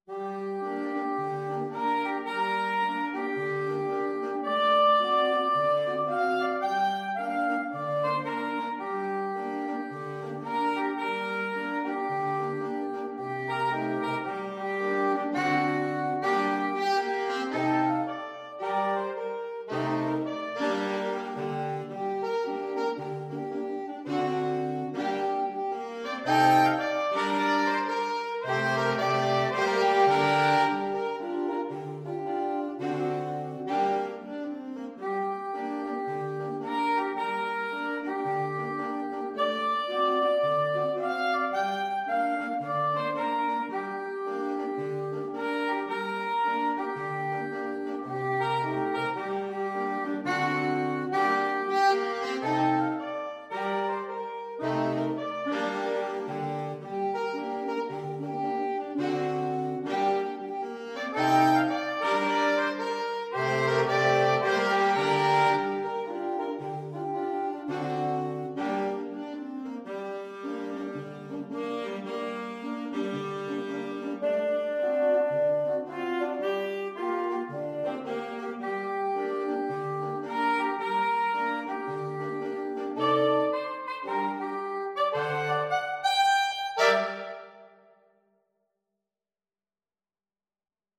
Moderate Gospel , Swung = c.110
4/4 (View more 4/4 Music)